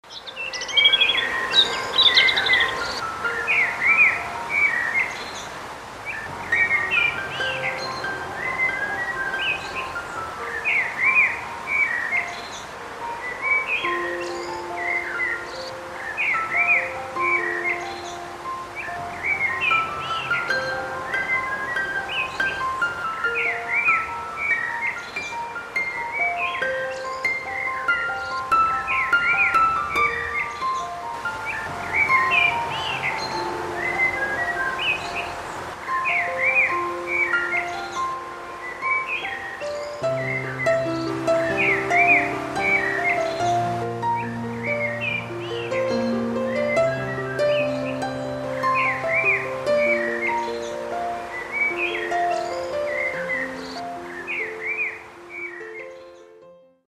Garden-Birds-Singing-and-Chirping_-Relaxing-Bird-S.mp3